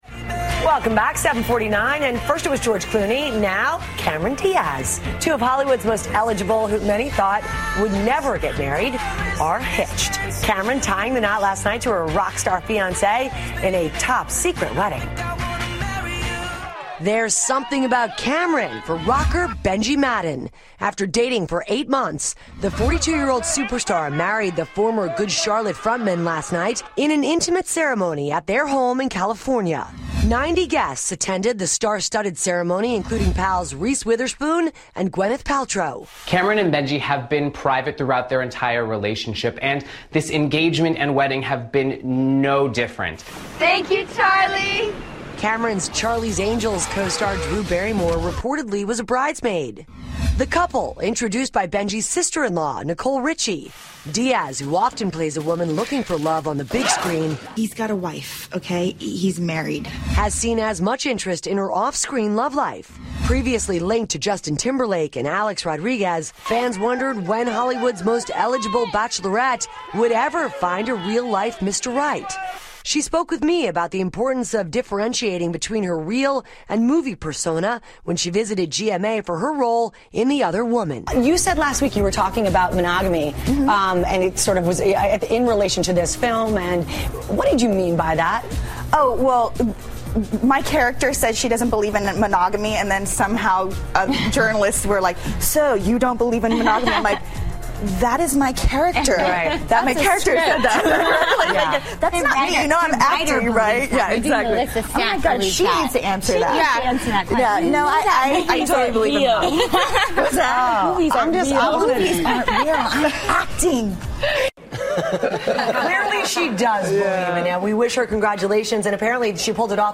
访谈录 “霹雳娇娃”卡梅隆·迪亚茨与吉他手闪婚 听力文件下载—在线英语听力室